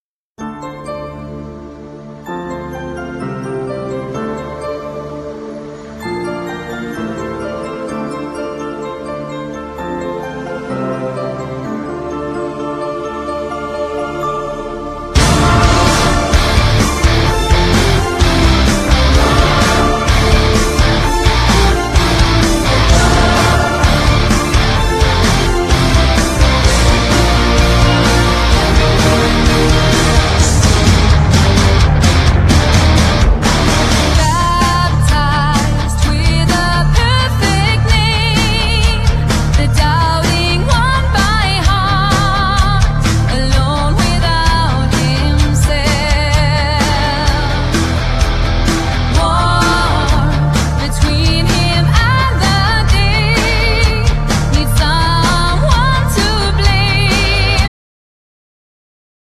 Genere : Pop /ROCK